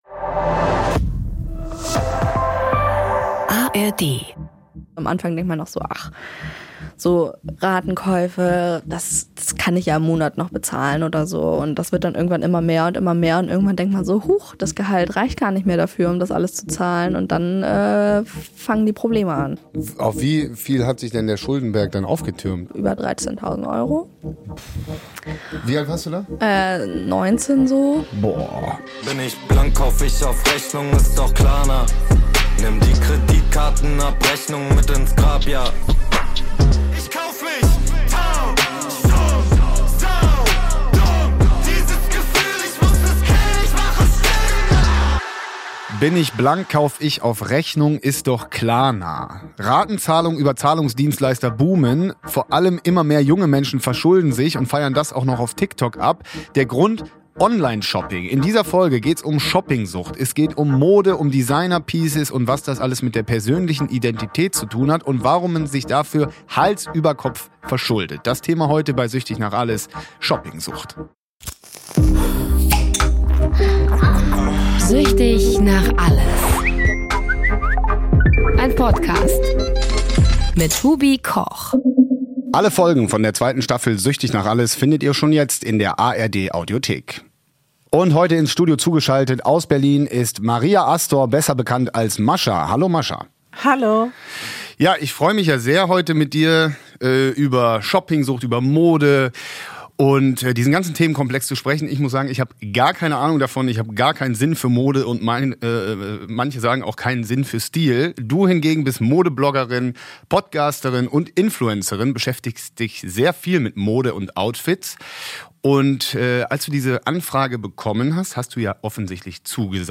Die Aufnahmen für diesen Podcast entstanden bereits im Sommer 2023.